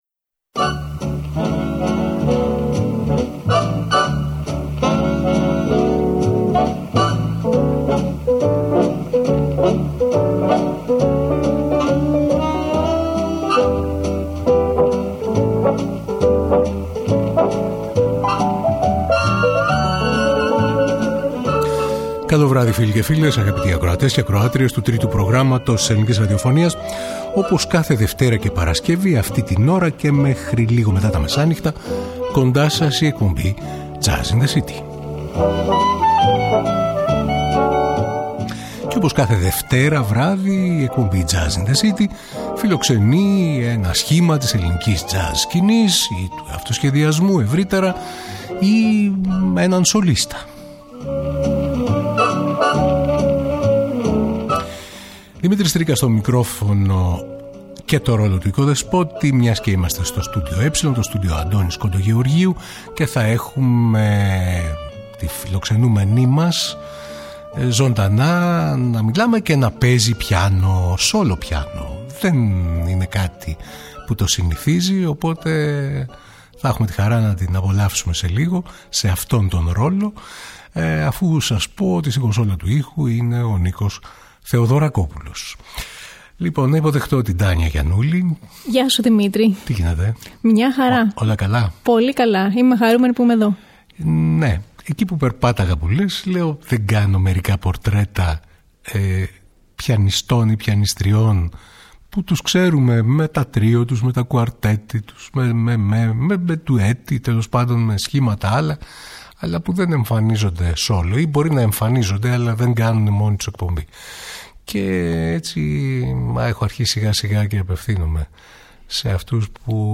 H πιανίστρια και συνθέτρια
live, solo piano
Τζαζ